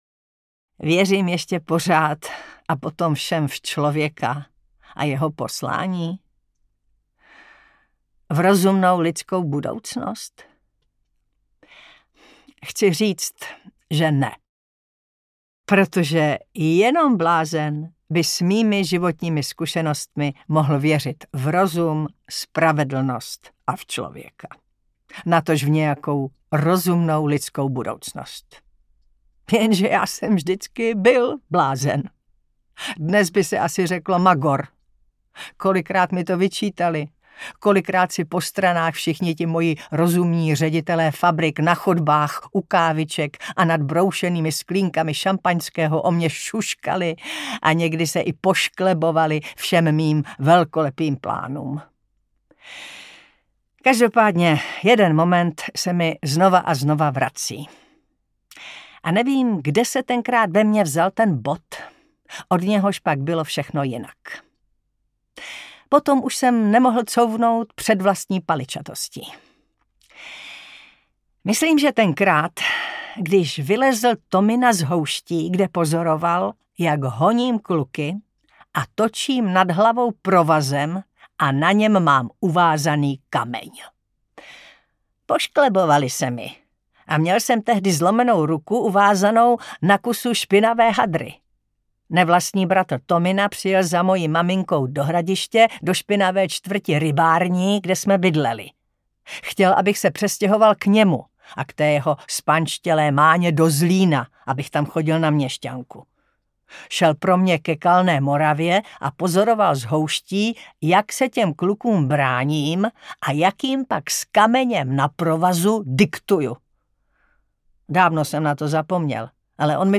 S Baťou v džungli audiokniha
Ukázka z knihy
Čte Taťjana Medvecká.
Vyrobilo studio Soundguru.